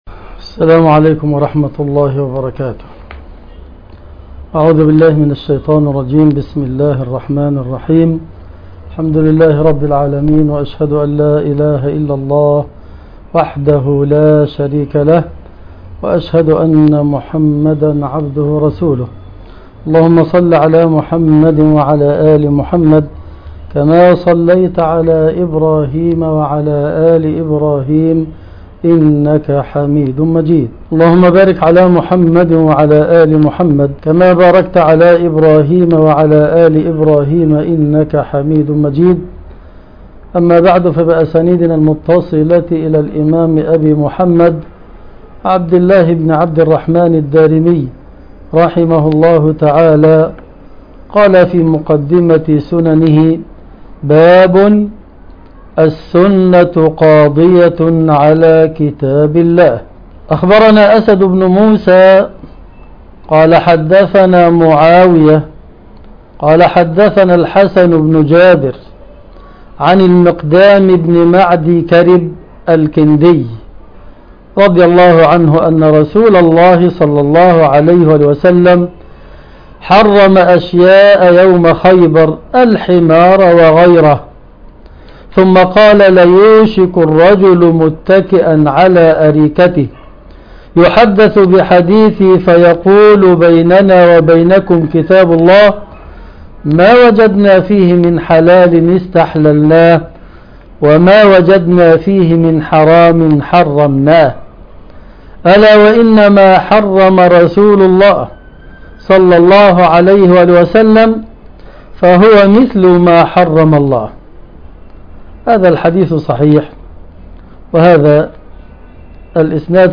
الدرس ( 43) شرح سنن الدارمي